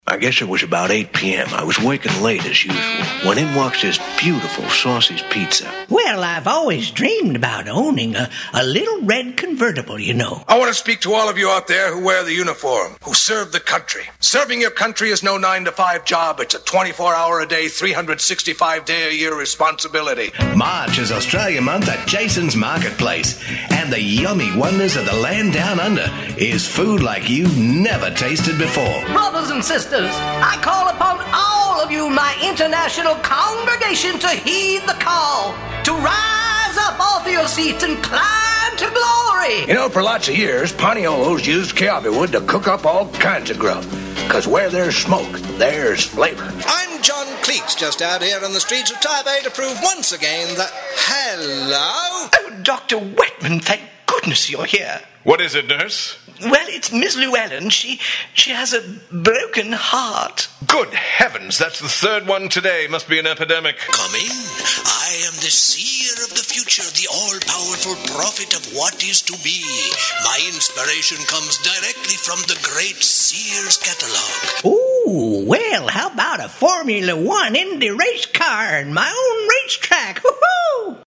A VOICE THAT\'S COMPLETELY DIFFERENT - WARM, GENUINE, FRIENDLY. APPROACHABLE, CONVERSATIONAL, YET AUTHORITATIVE, AND BELIEVABLE - AND A MASTER OF MANY DIALECTS AND ACCENTS, AS WELL.
Sprechprobe: Sonstiges (Muttersprache):